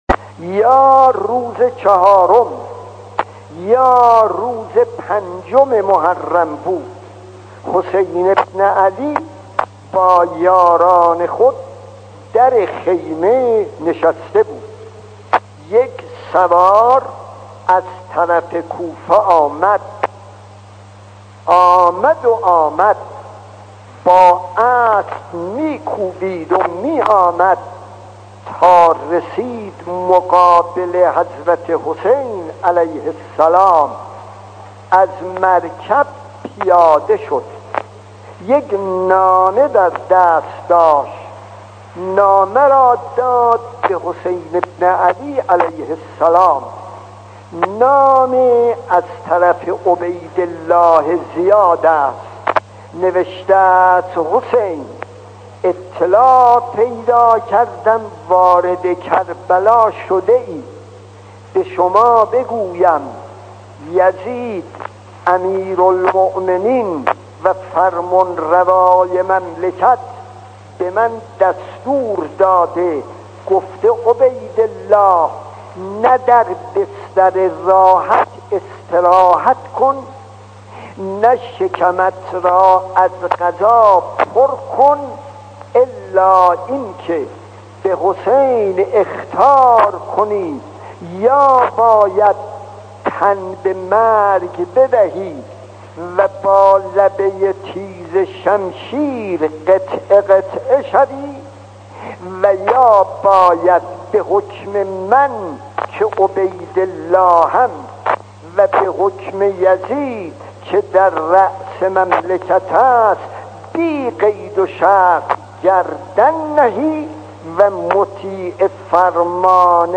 داستان 18 : امام حسین و نامه عبیدالله ابن زیاد خطیب: استاد فلسفی مدت زمان: 00:09:12